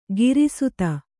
♪ giri suta